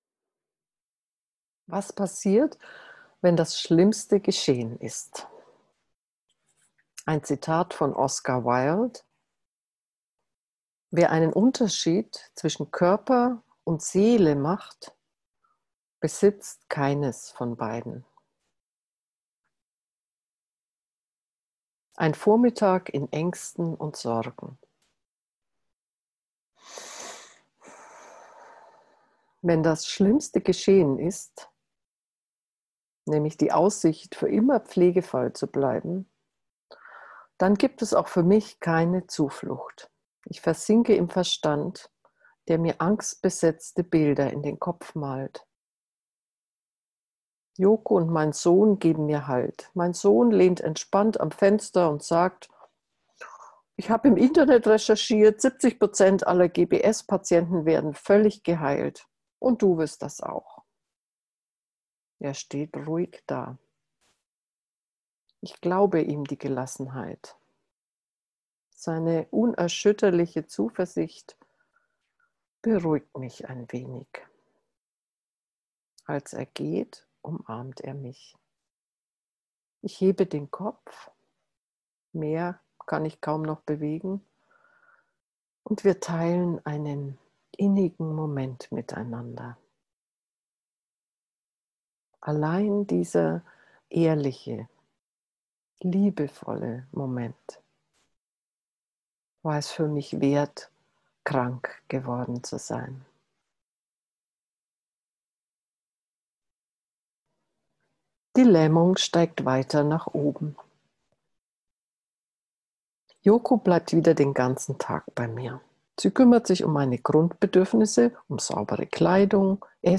Buchlesung